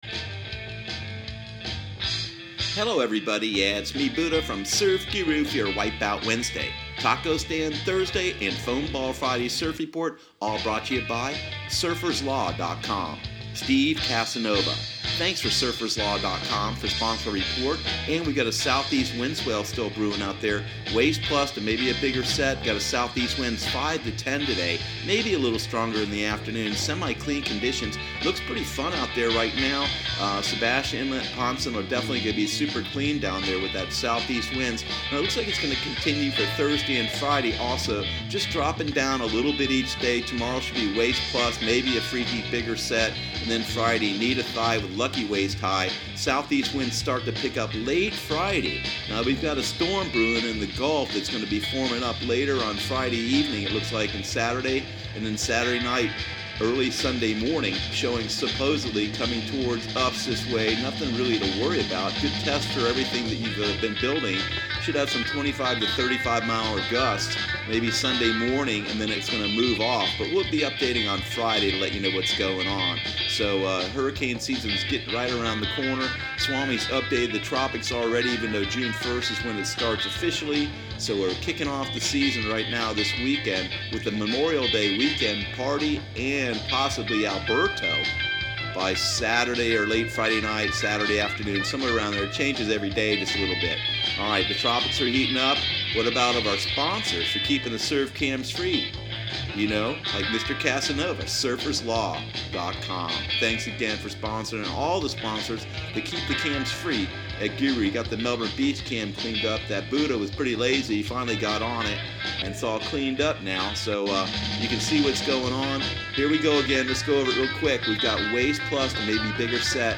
Surf Guru Surf Report and Forecast 05/23/2018 Audio surf report and surf forecast on May 23 for Central Florida and the Southeast.